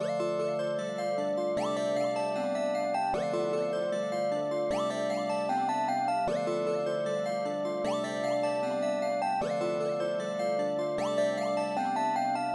Tag: 153 bpm Trap Loops Synth Loops 2.11 MB wav Key : Unknown